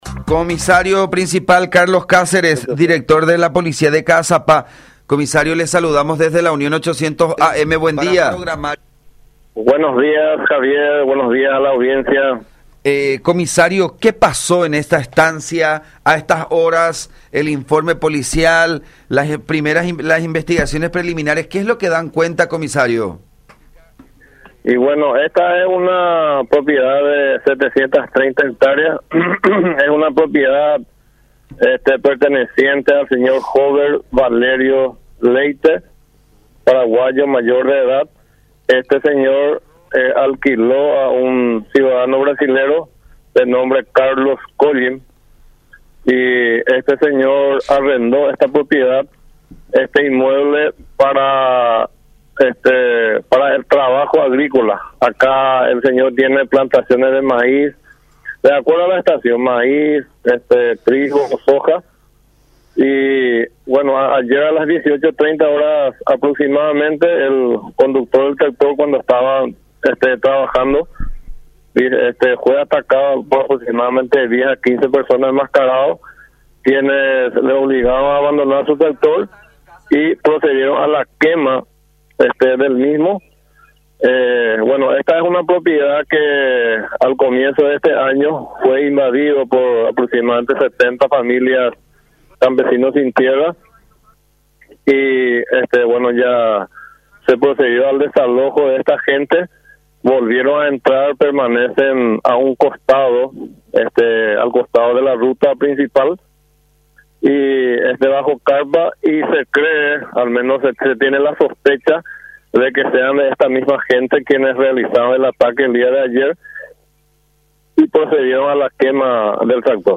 “Esta propiedad, a comienzos de este año, fue invadida por 70 familias de campesinos ‘sin tierra’. Luego se procedió al desalojo de esta gente, volvieron a entrar y permanecen en un costado. Se tiene la sospecha de que sean las mismas personas las que atacaron”, explicó Cáceres en diálogo con La Unión.
06-Crio.-Ppal.-Carlos-Cáceres-director-de-Policía-de-Caazapá.mp3